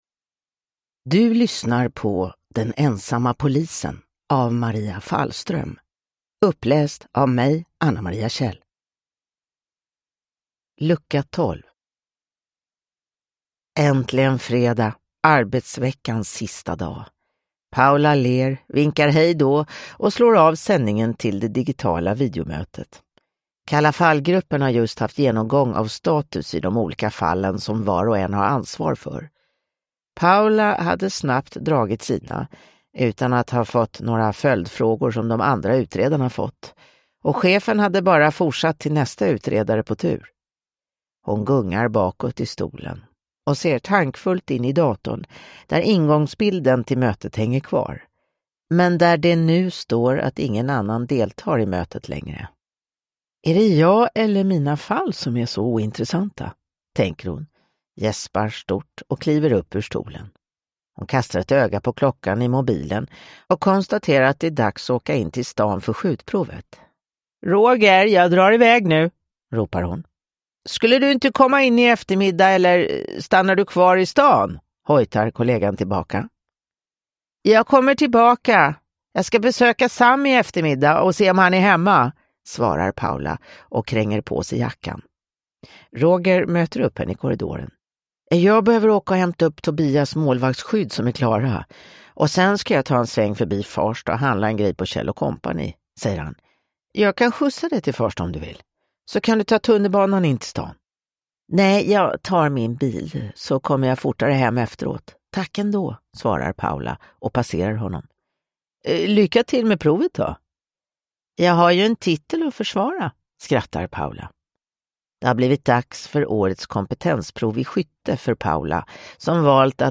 Den ensamma polisen: Lucka 12 – Ljudbok